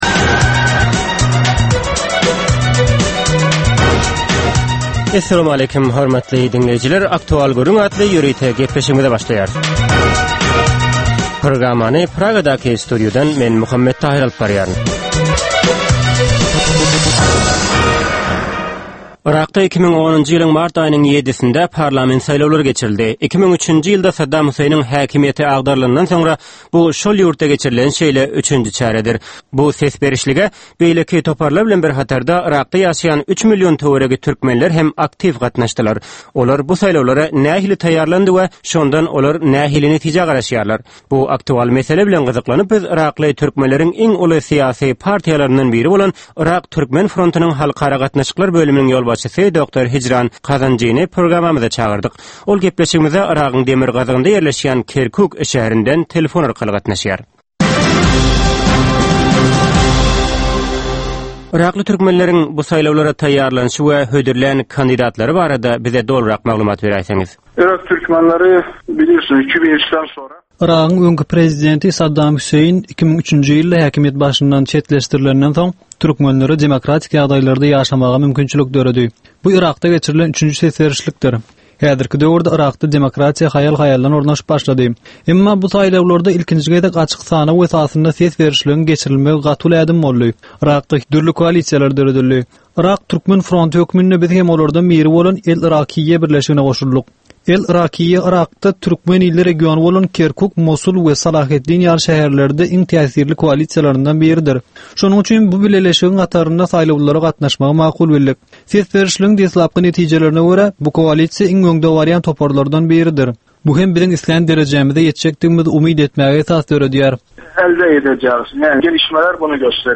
Hepdäniň dowamynda Türkmenistanda ýa-da halkara arenasynda ýüze çykan, bolup geçen möhüm wakalar, meseleler barada anyk bir bilermen ýa-da synçy bilen geçirilýän 10 minutlyk ýörite söhbetdeşlik. Bu söhbetdeşlikde anyk bir waka ýa-da mesele barada synçy ýa-da bilermen bilen aktual gürründeşlik geçirilýär we meseläniň dürli ugurlary barada pikir alyşylýar.